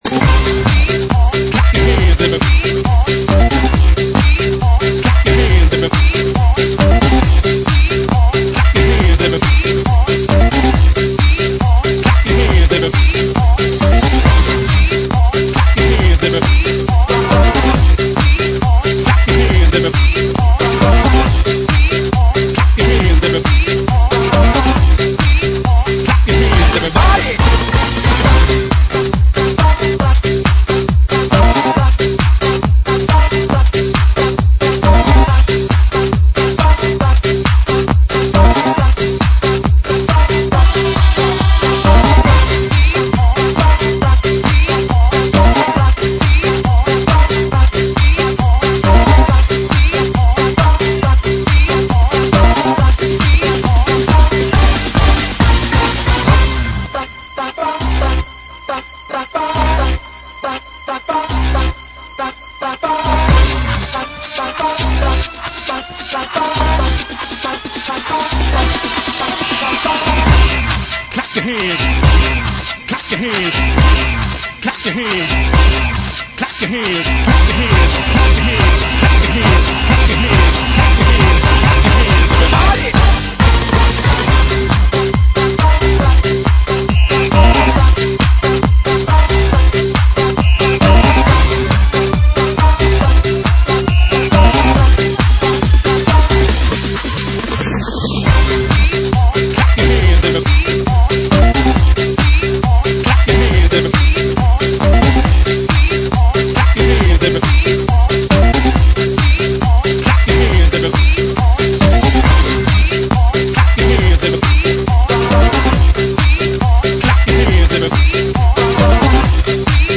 GENERO: DANCE